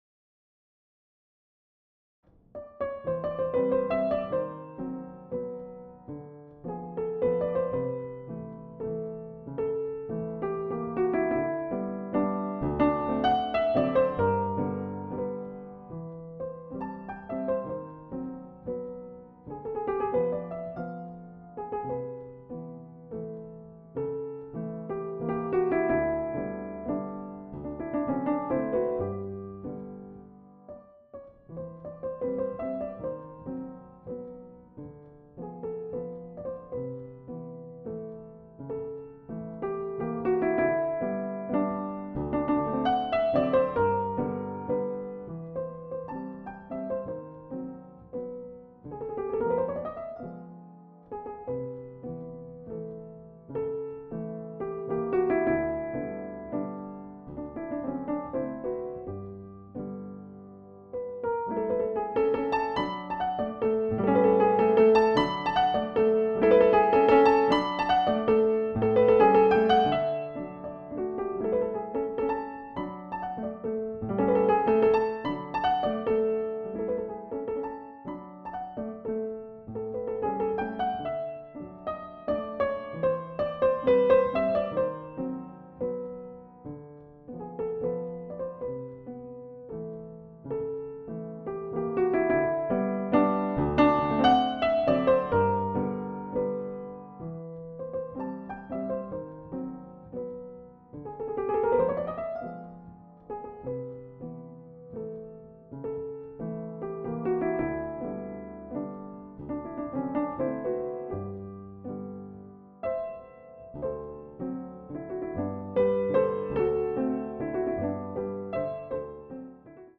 Pianoteq 7: I. Pleyel (1835)